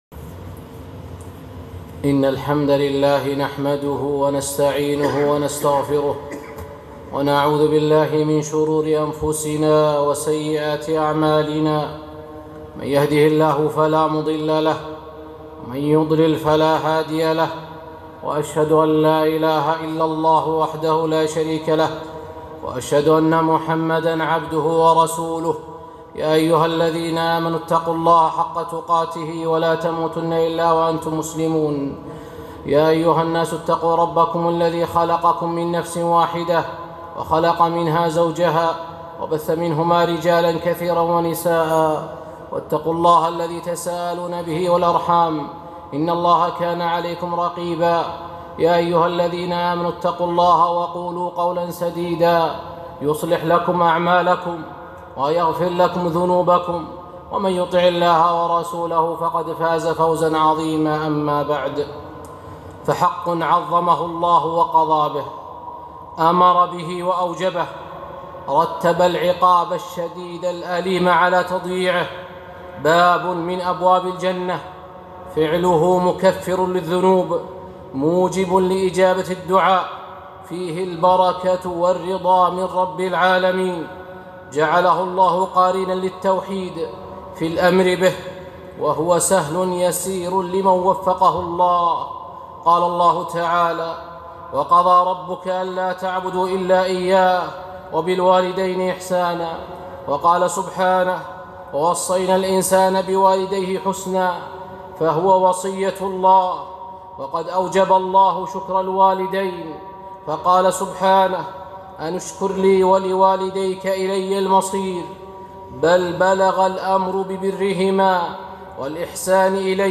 خطبة - عبودية البر